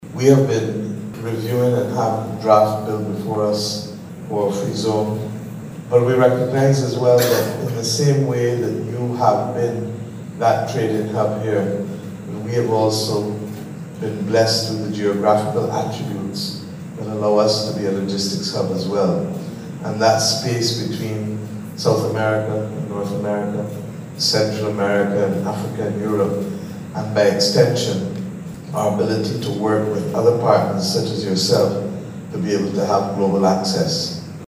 The plans were outlined by Prime Minister Mia Amor Mottley this morning when the Barbados delegation met with key leaders of the Dubai Free Zone which is described as the world leader in that business.
Prime Minister Mia Amor Mottley.